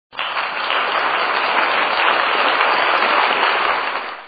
applause